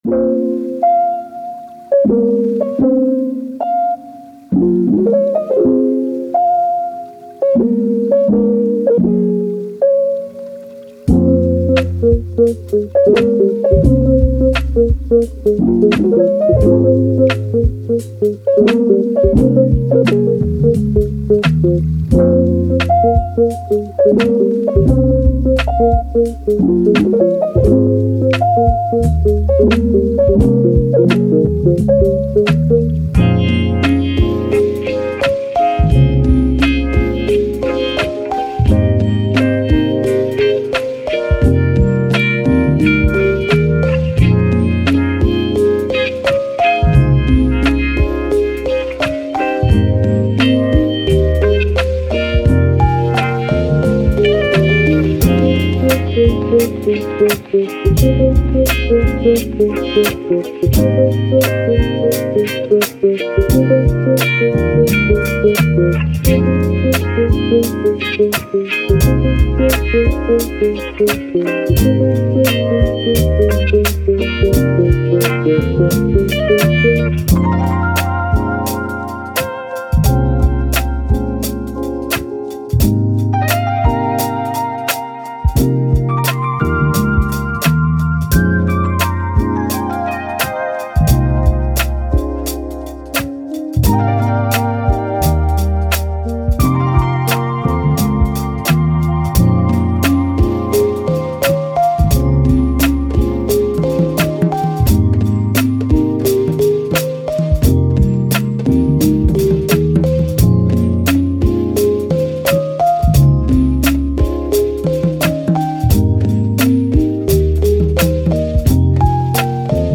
Hip Hop, Playful, Positive, Lofi